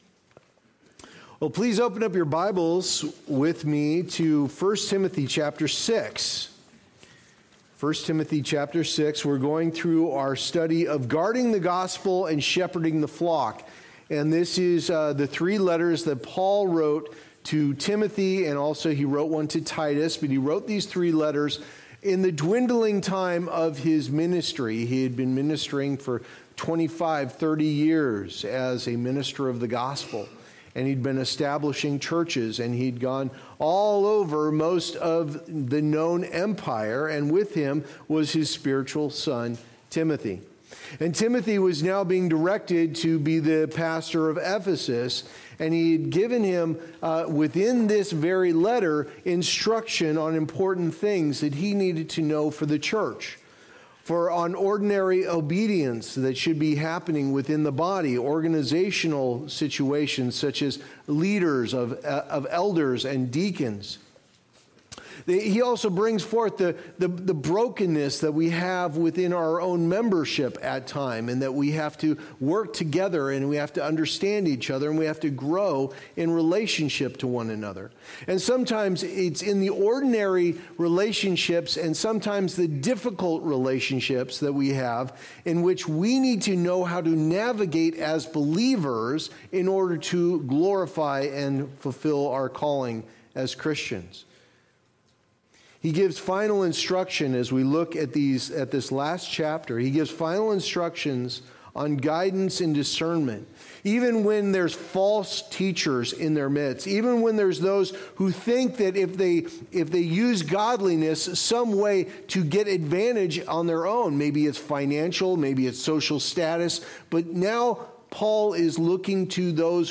Guard the Gospel & Shepherd the Flock Passage: I Timothy 6:1-10 Services: Sunday Morning Service Download Files Notes Previous Next